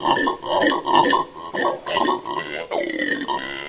Такой звук издает обезьяна ревун